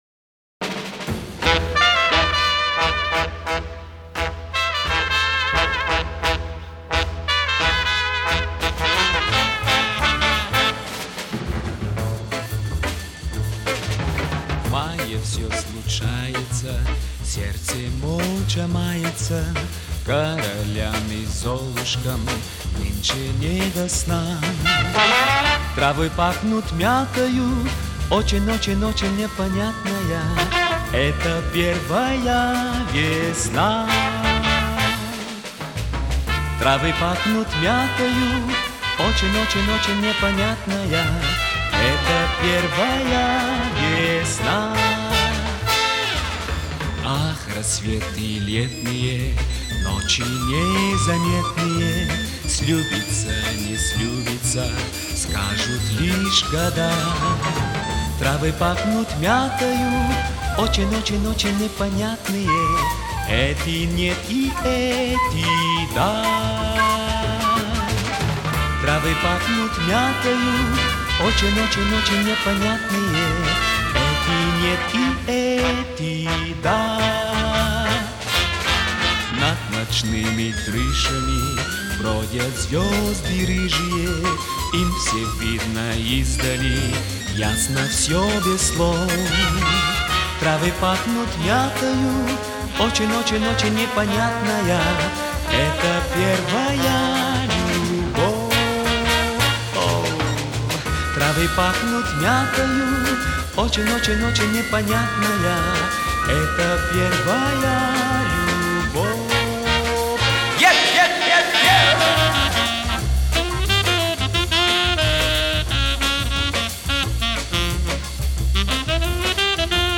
Звучала эта песенка в конце 60х Веселенькая. Ритмичная.